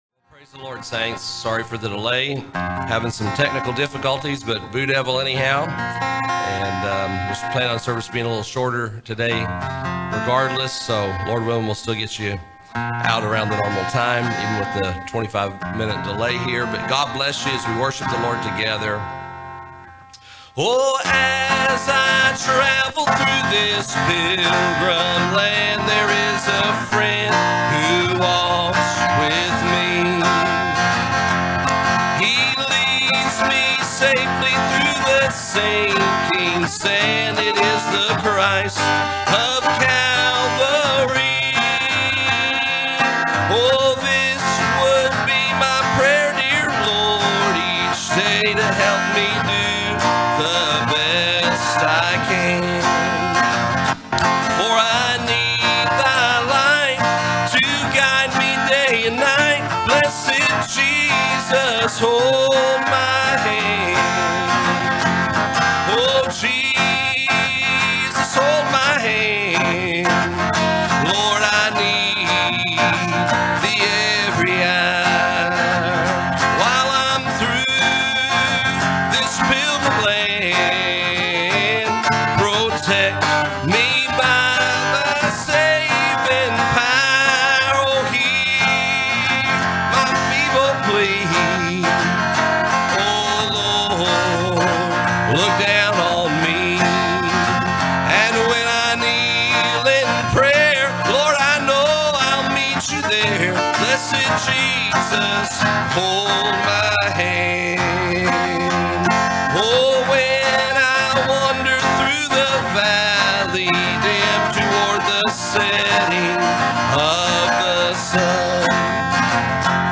Passage: Matthew 7:13-14 Service Type: Sunday Morning